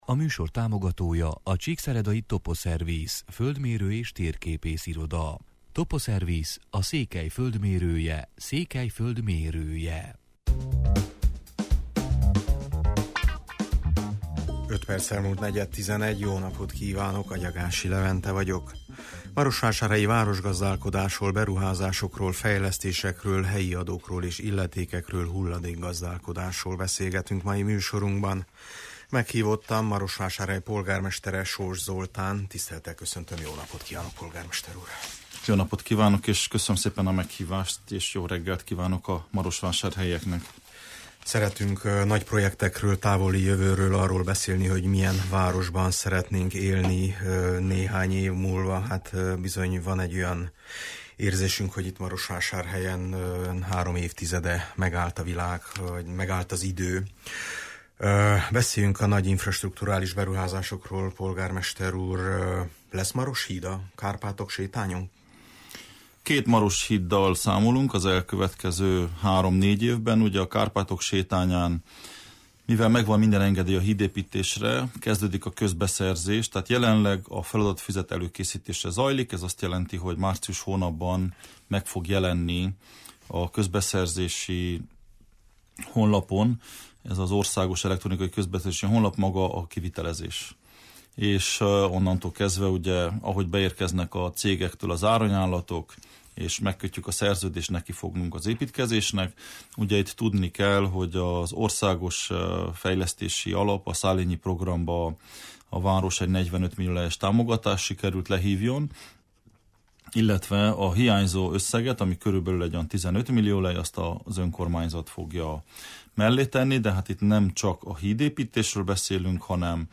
Meghívottam Marosvásárhely polgármestere, Soós Zoltán: